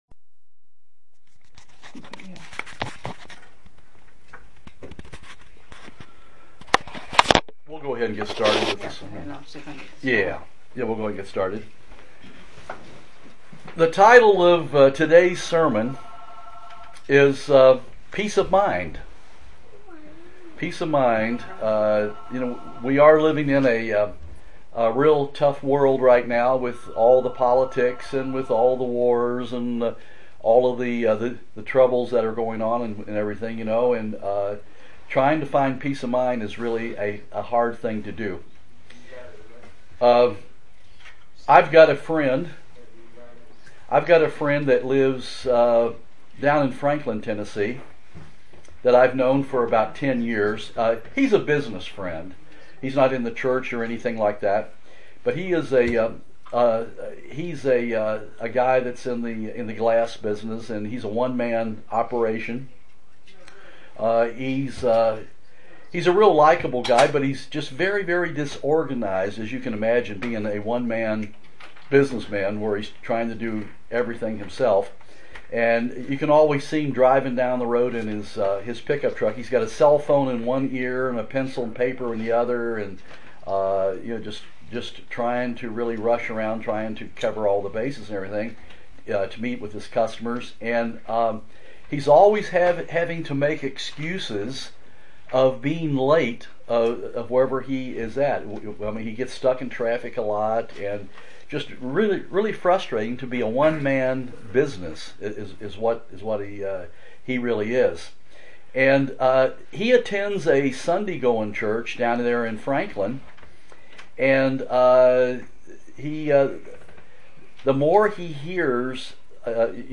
Given in Jackson, TN
UCG Sermon Studying the bible?